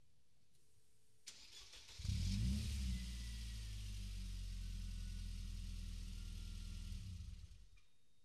试车会。190E " 17短距离扫频
描述：5秒钟的加速扫描，主角是奔驰190E16V。用Rode NT1a在车前约30英尺处进行录音，在车间里。
标签： 弗鲁姆 奔驰 车辆 测力计 测功机 奔驰 汽车 发动机
声道立体声